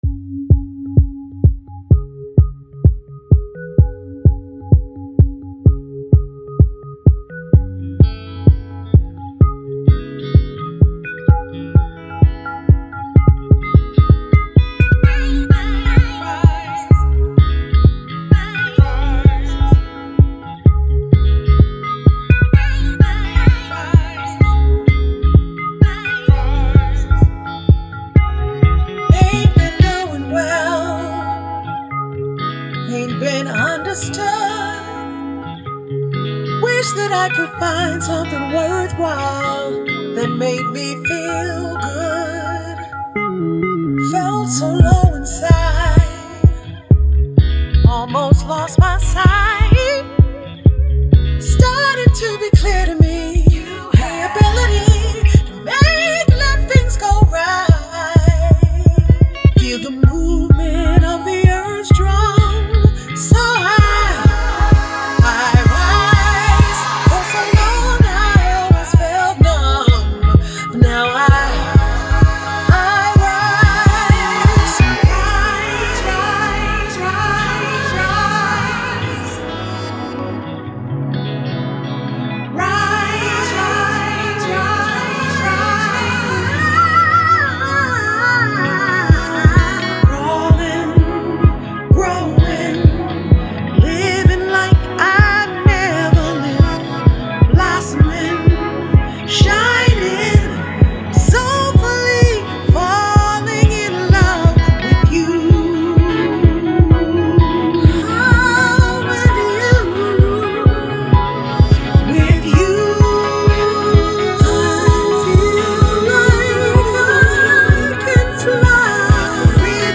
vocals
trumpet
flute
keys